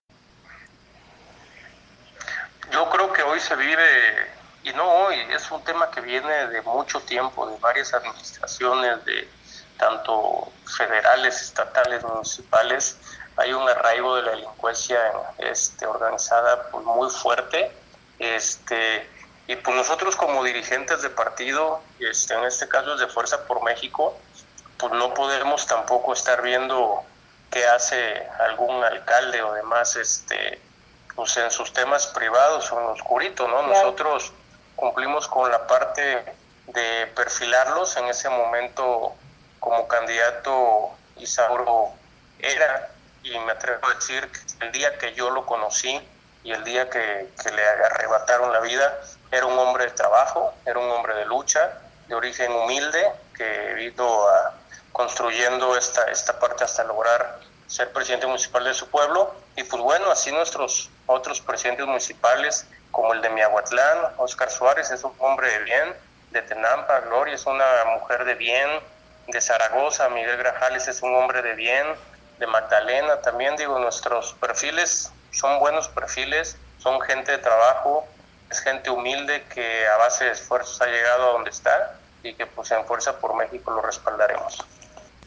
En entrevista